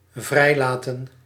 Ääntäminen
IPA: [li.be.ʁe]